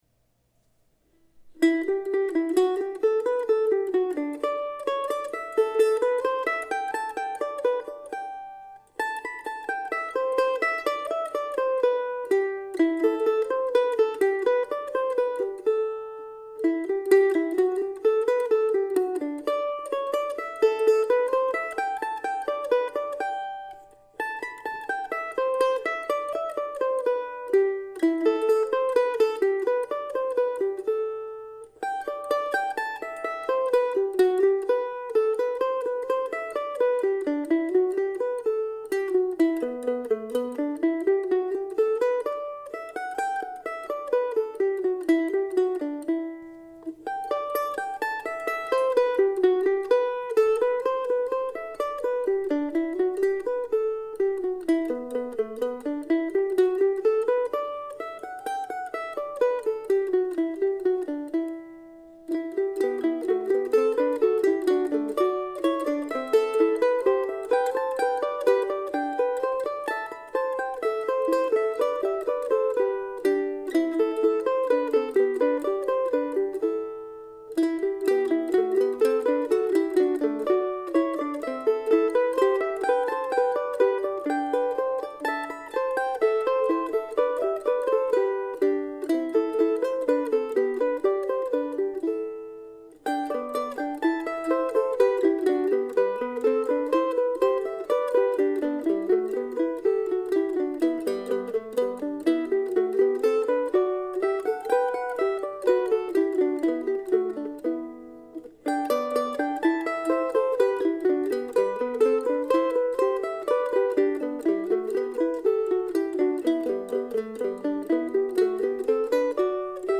I played the tune using the melody only on the first pass and then added the harmony part on the second time through.